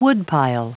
Prononciation du mot woodpile en anglais (fichier audio)
Prononciation du mot : woodpile
woodpile.wav